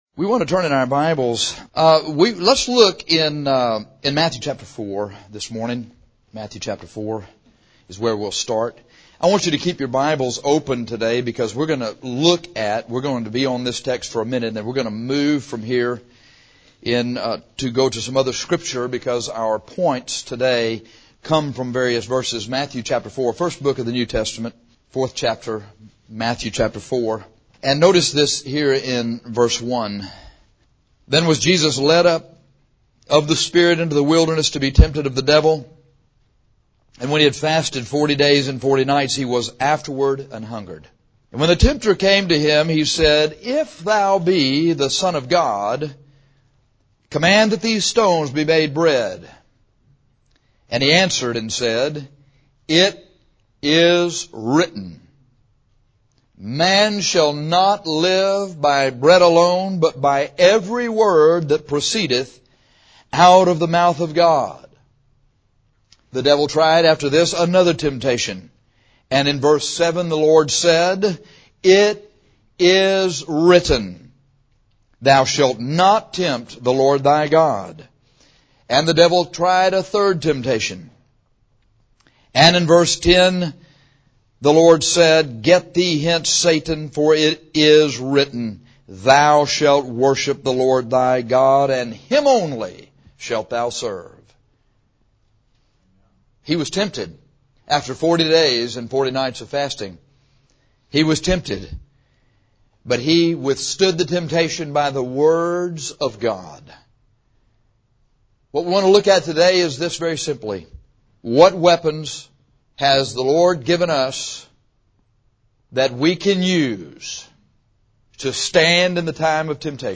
To keep sin from having dominion over you, you need to fight it with the spiritual weapons against sin. This sermon examines seven excellent weapons.